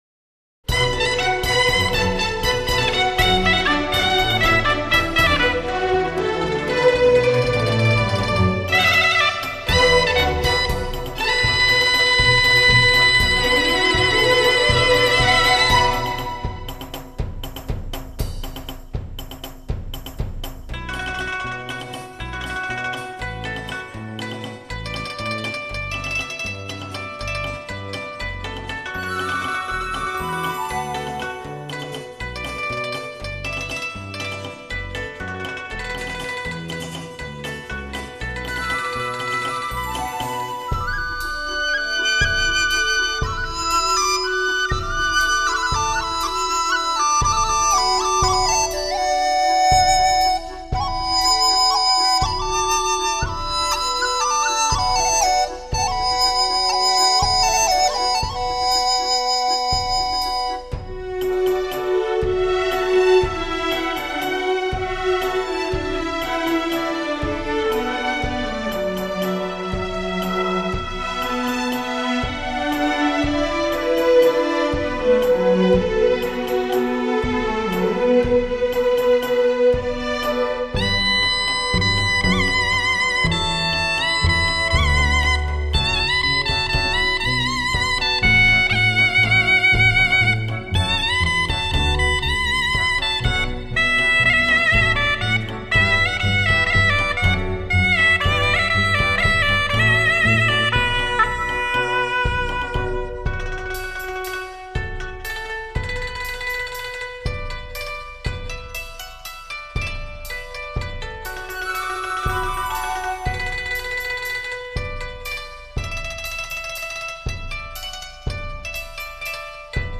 专辑类型：纯音乐
结合西洋乐器，乐队由西洋管弦乐与中国民族乐器混合组成。
最新数码录音，十分悦耳，值得收藏。